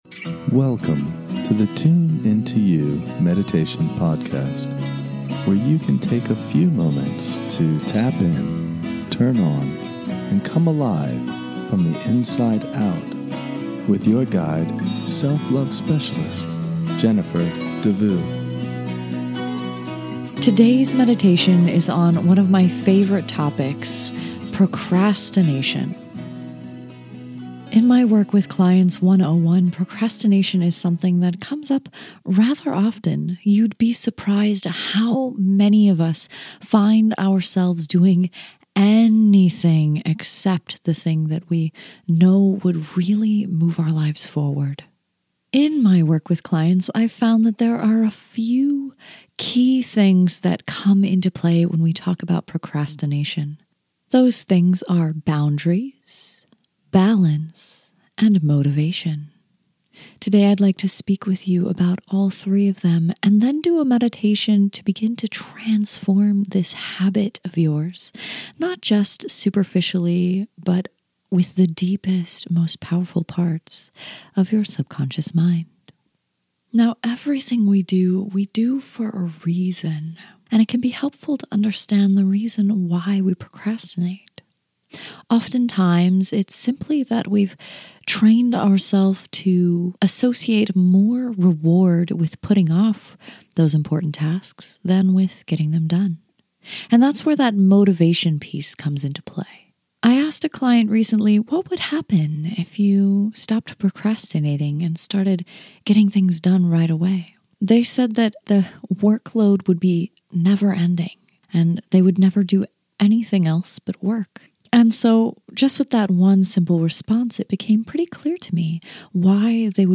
In this short guided meditation, I call out all the reasons we procrastinate and how to rewire your mind to want to get things done.